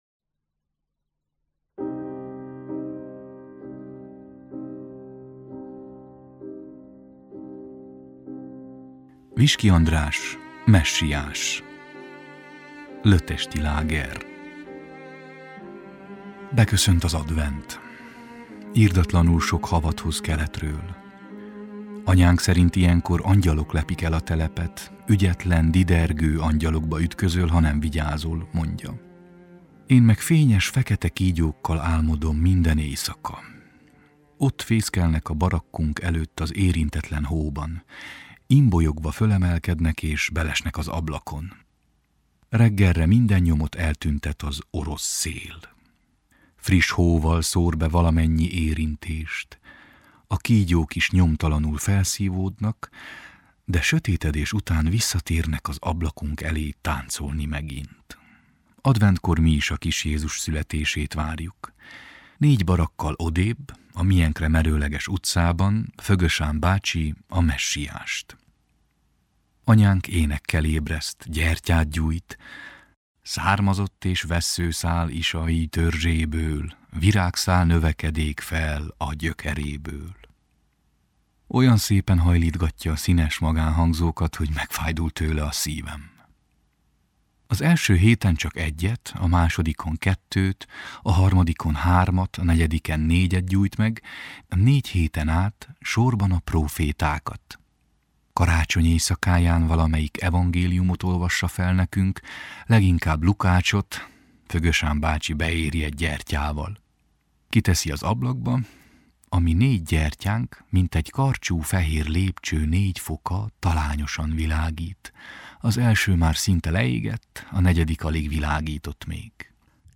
Zenei illusztráció – Fauré: Élégie op.24